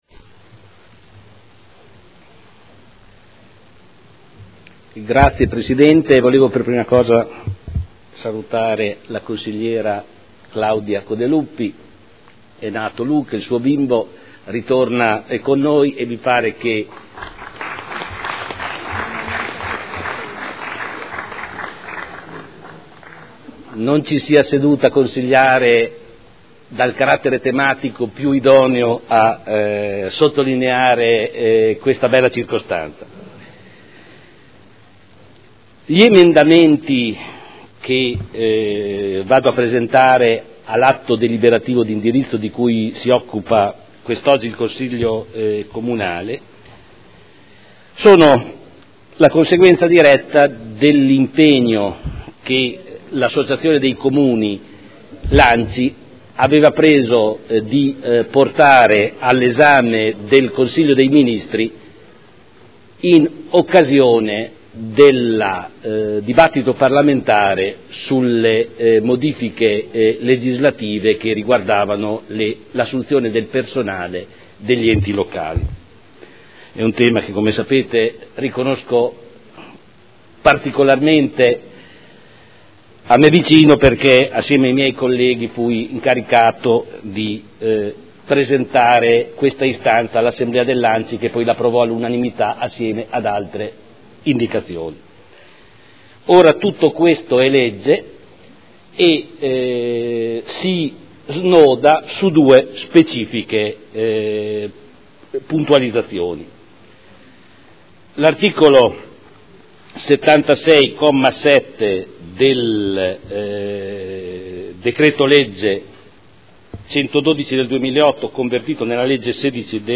Seduta del 03/05/2012. Presenta altro emendamento a proposta di deliberazione: Linee di indirizzo per la costituzione di una Fondazione finalizzata a gestire servizi scolastici ed educativi comunali 0/6 anni